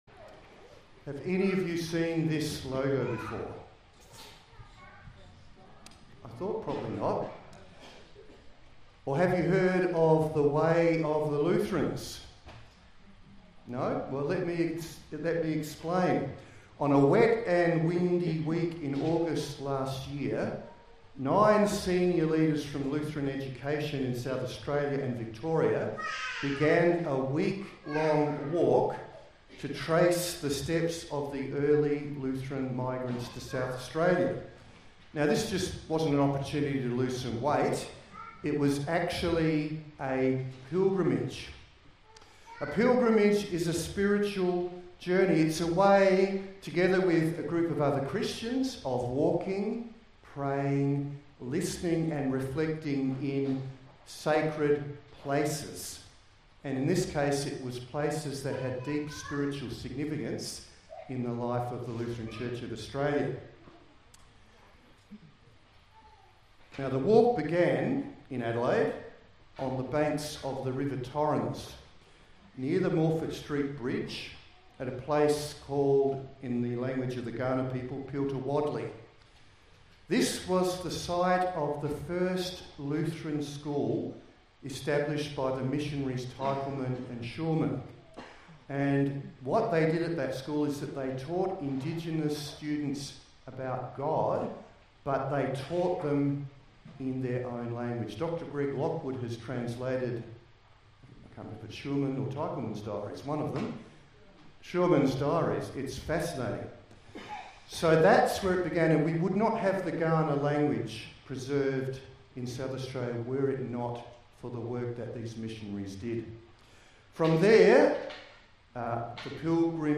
Sermon by Bishop Andrew Brook